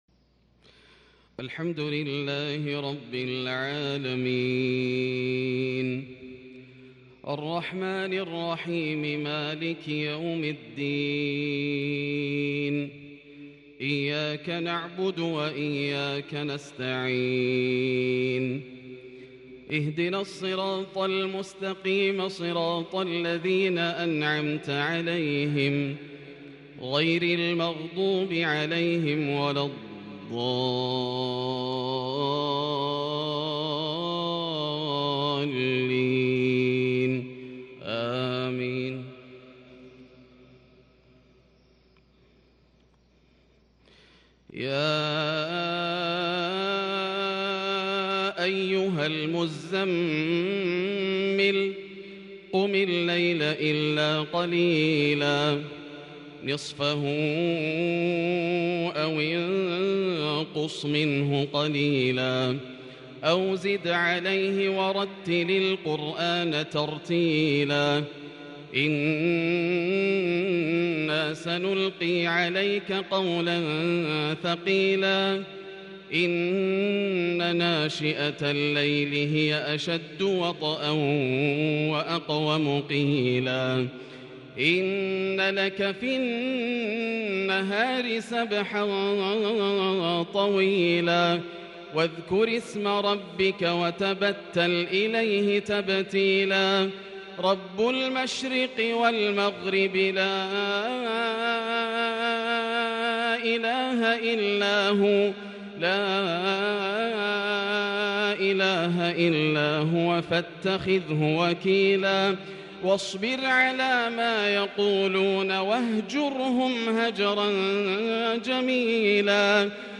مغرب السبت 1-7-1442هـ لسورة المزمل | Maghrib prayer from Surat AlMuzzammil 13/2/2021 > 1442 🕋 > الفروض - تلاوات الحرمين